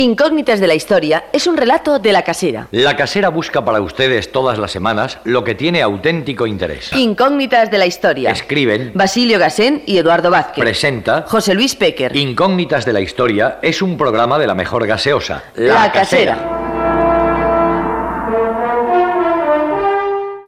Publicitat i equip del programa.
Divulgació